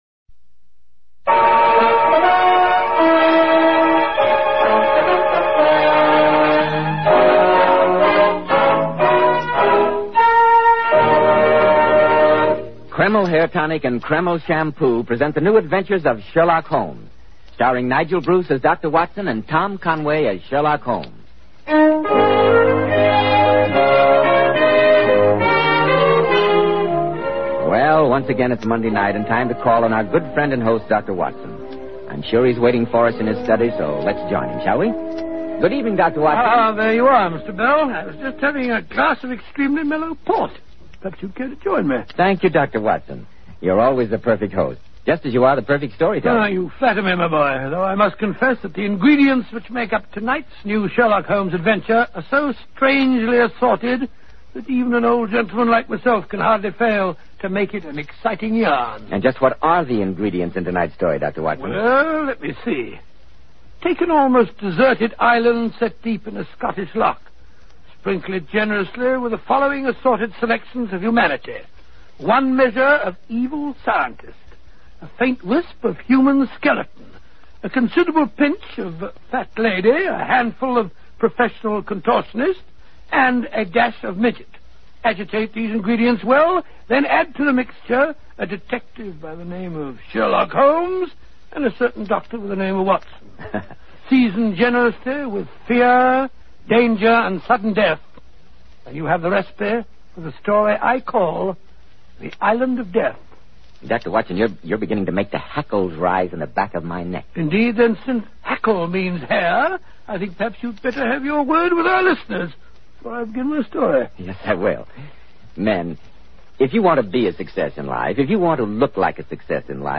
Radio Show Drama with Sherlock Holmes - The Island Of Death 1947